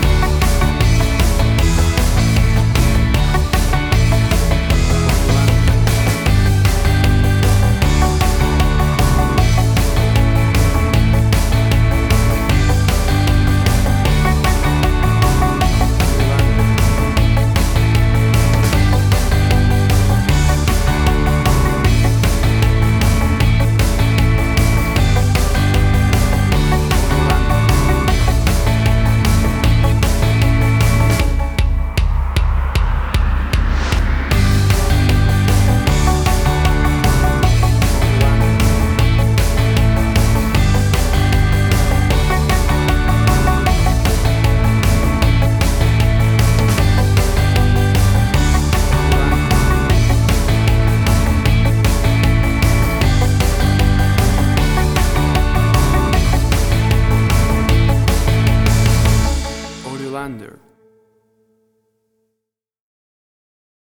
WAV Sample Rate: 16-Bit stereo, 44.1 kHz
Tempo (BPM): 156